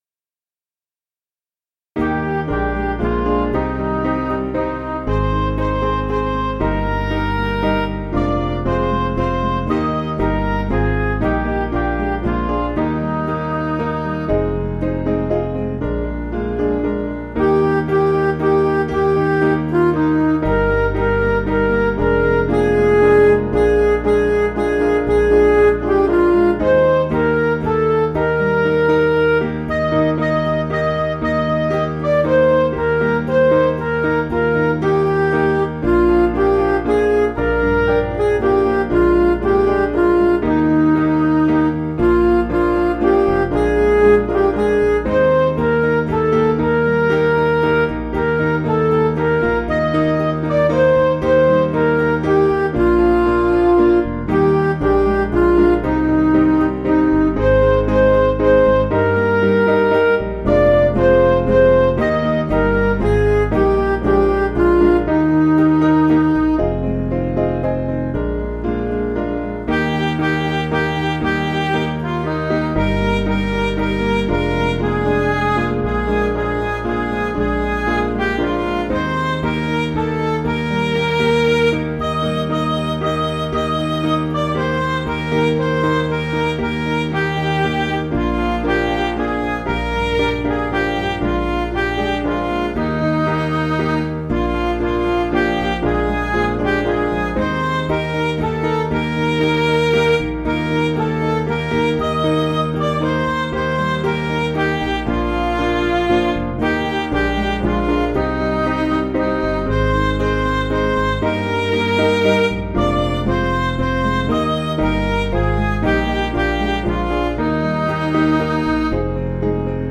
Piano & Instrumental
(CM)   4/Eb